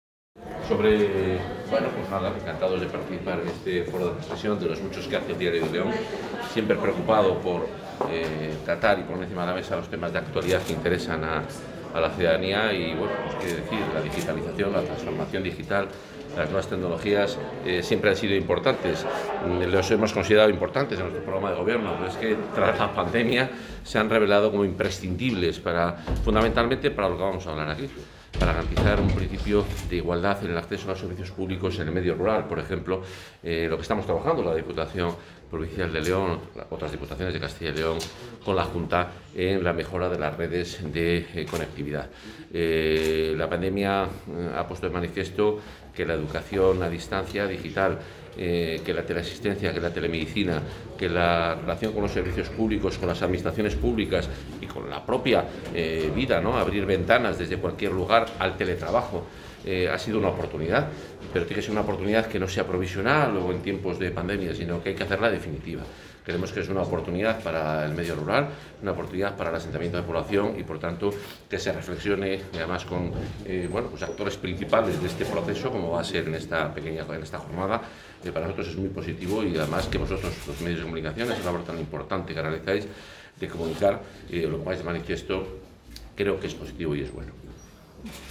Declaraciones del consejero de Fomento y Medio Ambiente.
El consejero de Fomento y Medio Ambiente, Juan Carlos Suárez-Quiñones, ha participado hoy en la jornada 'La digitalización como aliado en el medio rural' organizada por el Club de Prensa Diario de León.